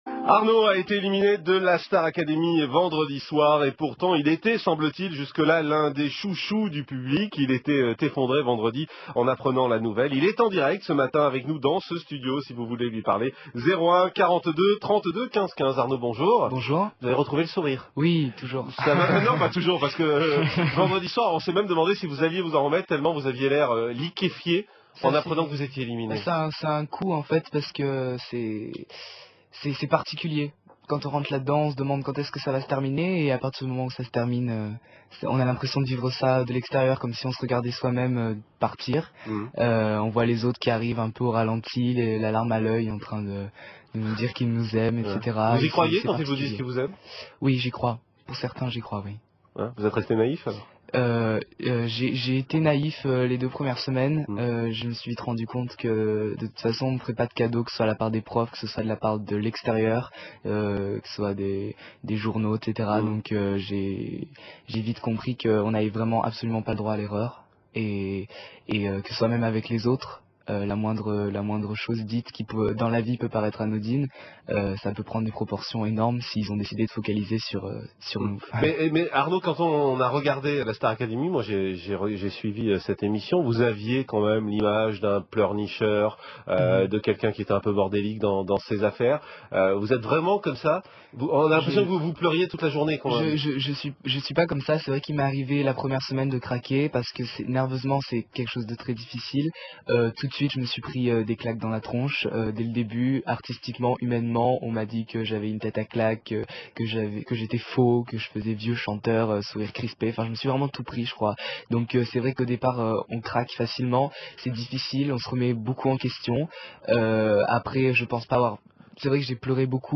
En tout cas , il s'exprime drôlement bien et avec une grande maturité pour son âge